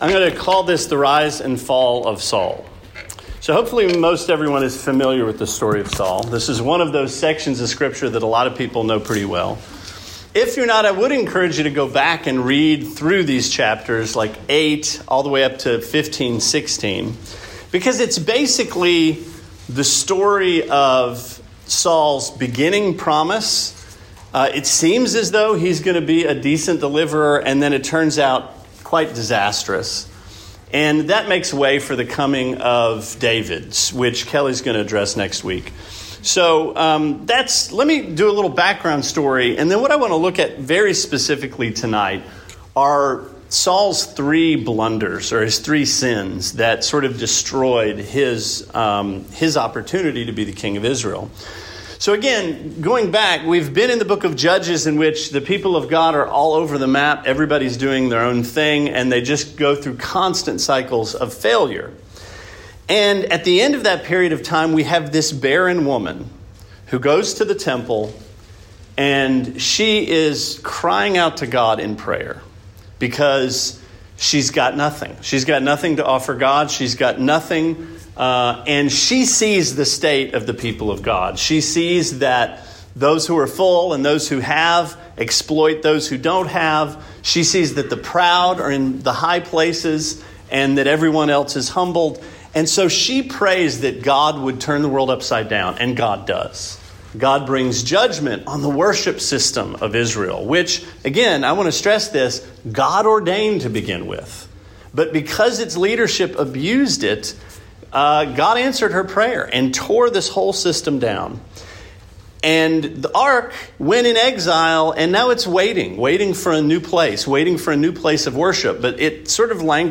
Sermon 6/19: The Rise and Fall of Saul